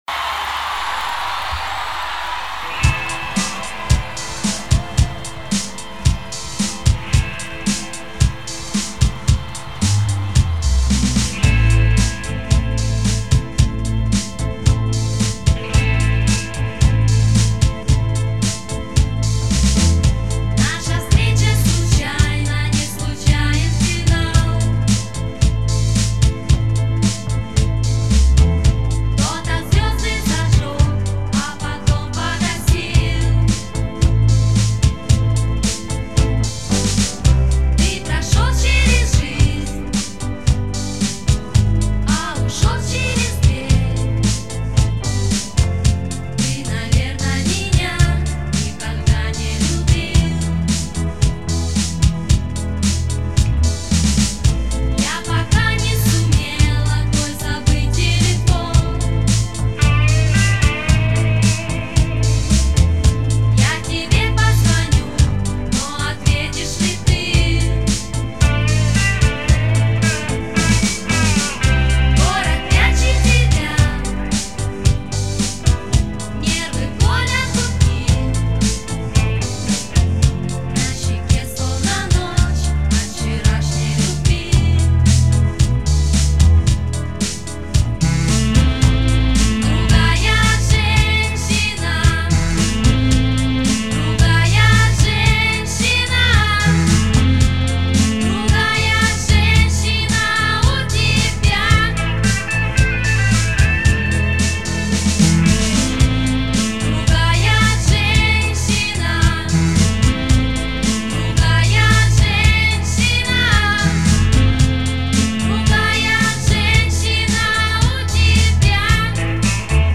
певец